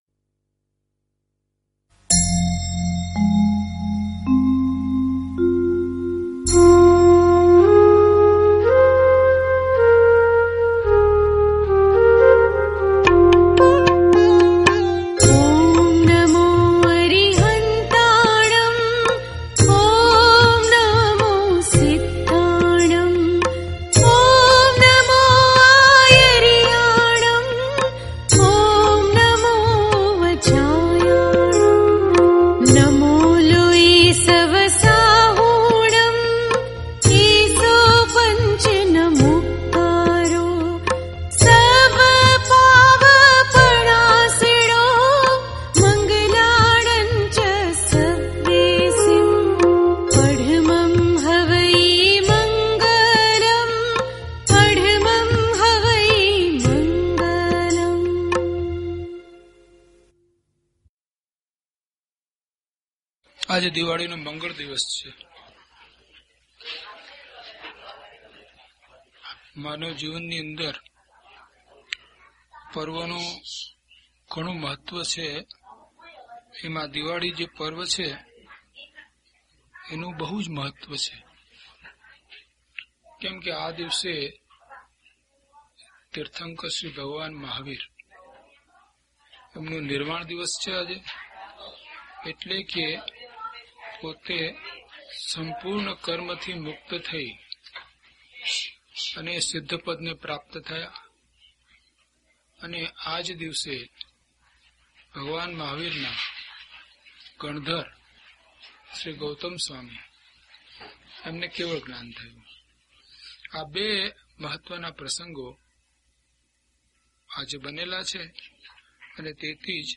DHP007 Apurva Avasar Gatha 5 to 8 - Pravachan.mp3